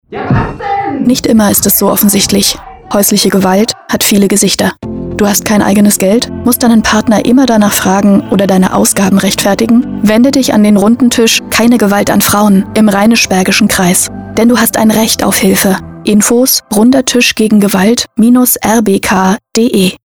Funkspots: Sie werden im Aktionszeitraum wieder bei Radio Berg zu hören sein!